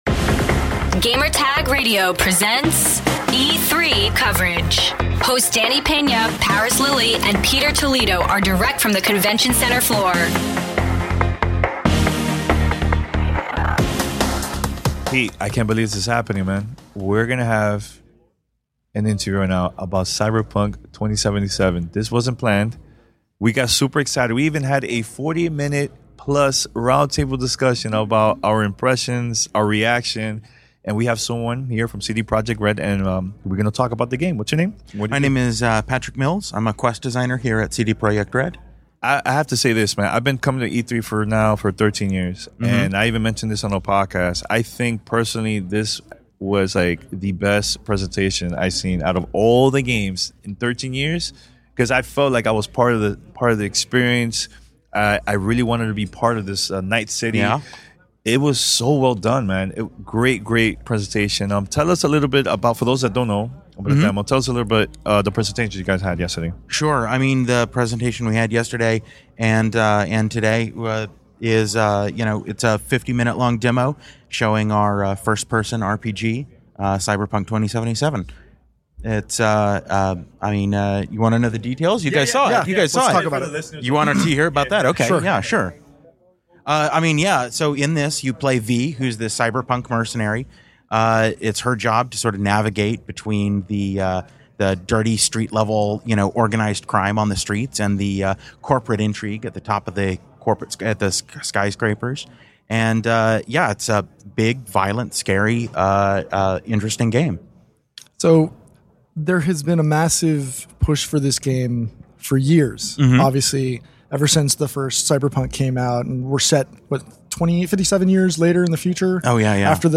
E3 2018: Cyberpunk 2077 Interview